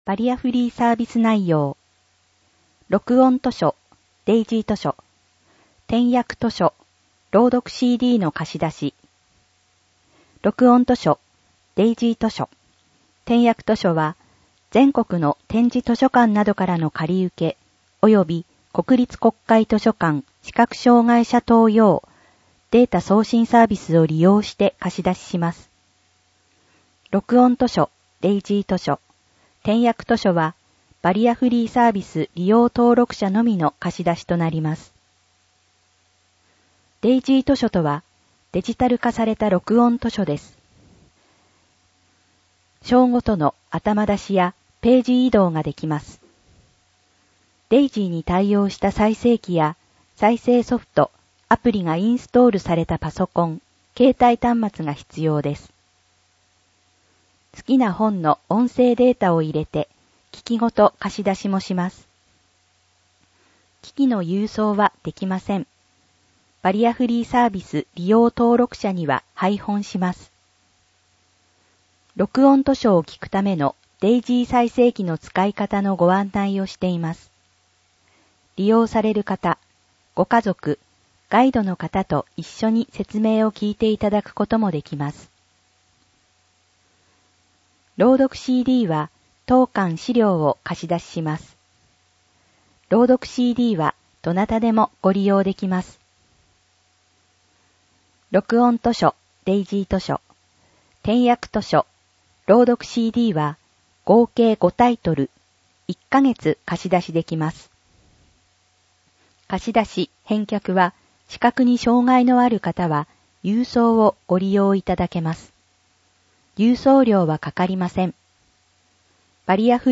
をクリックすると音声版の利用案内を聴くことができます。